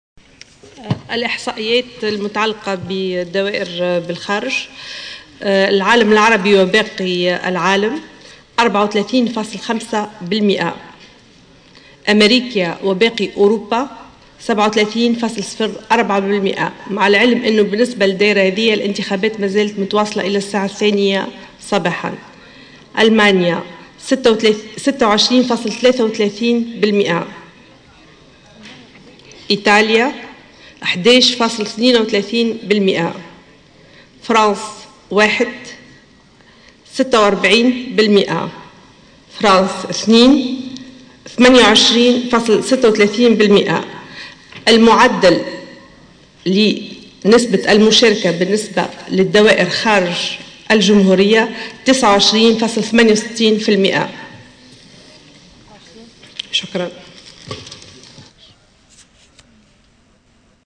Lamia Zargouni, membre de l’ISIE a déclaré lors de la conférence de presse organisée ce dimanche 23 novembre 2014, que le taux de participation au vote lors des élections présidentielles à l’étranger a atteint les 29.68%.